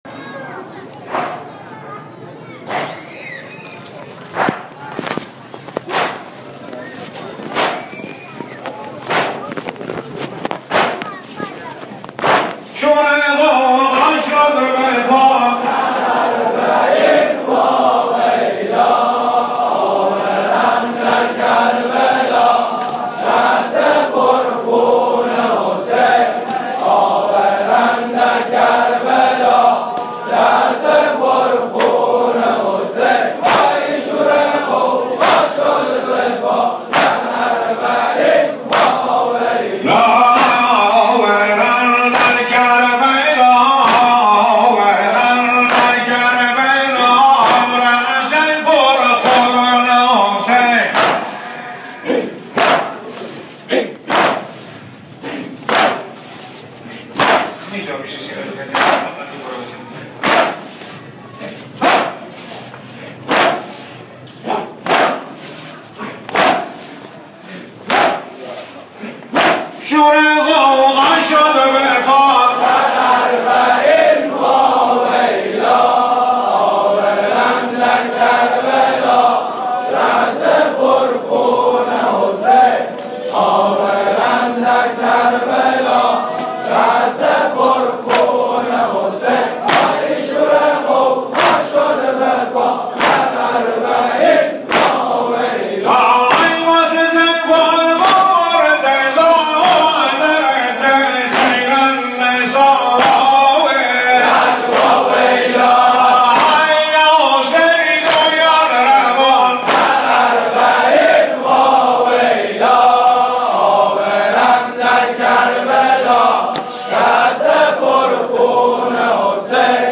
آوای لیان - دانلود نوحه و عزاداری سنتی بوشهر
مراسم سینه زنی اربعین ۱۳۹۴ | مسجد دهدشتی